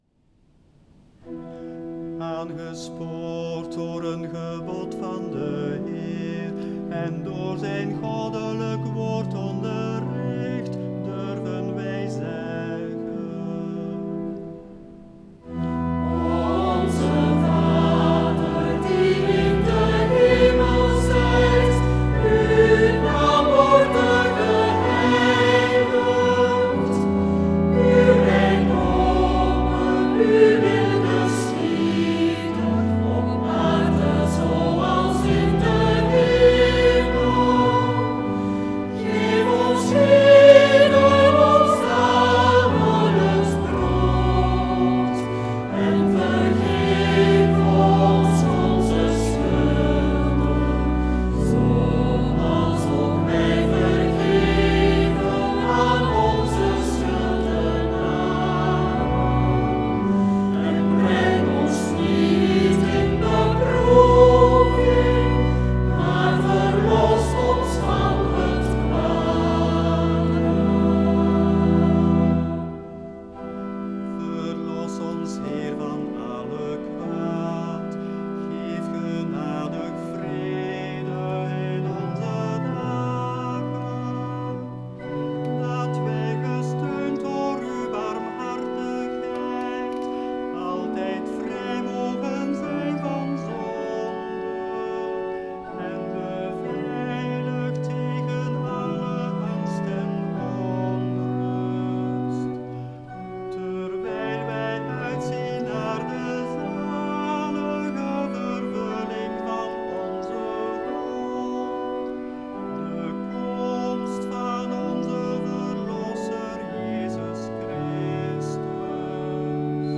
Leer het nieuwe Onzevader zingen (met partituur)
Download de partituren van het nieuwe gezongen Onzevader.